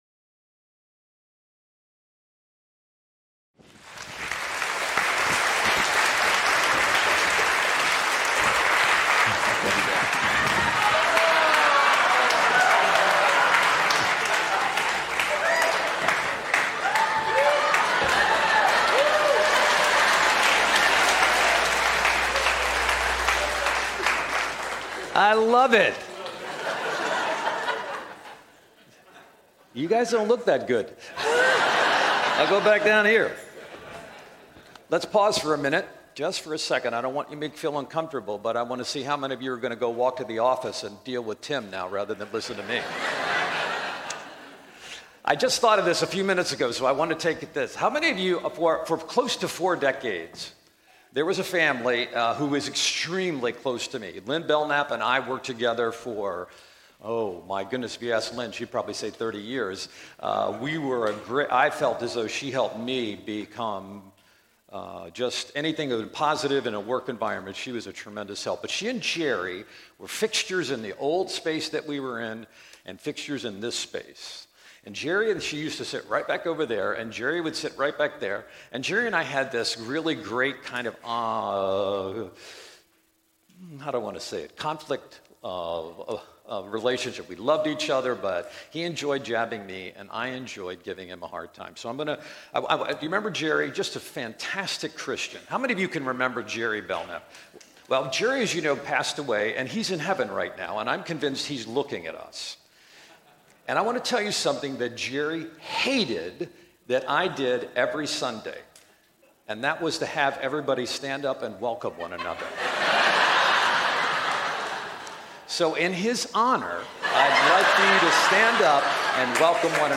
This morning in our sermon we will look in on a group of once deeply committed people...people who made God the King of their lives.